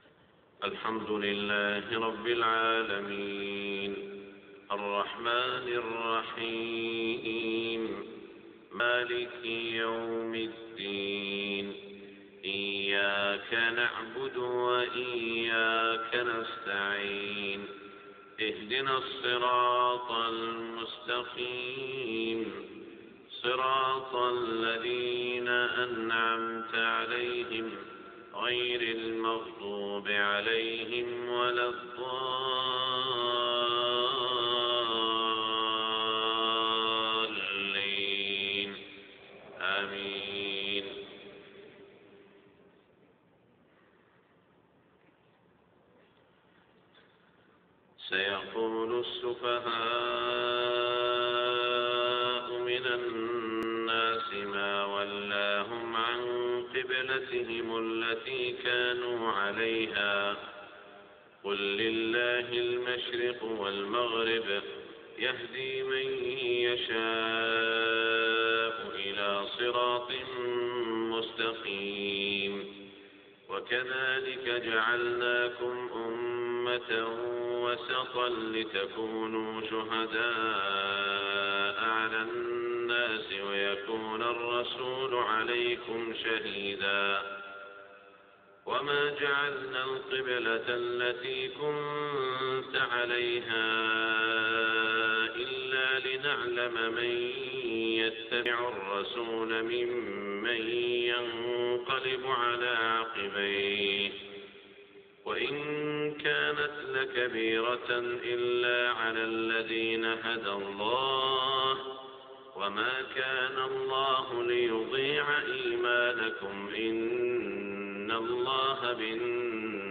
صلاة الفجر 18 شوال 1427هـ من سورة البقرة > 1427 🕋 > الفروض - تلاوات الحرمين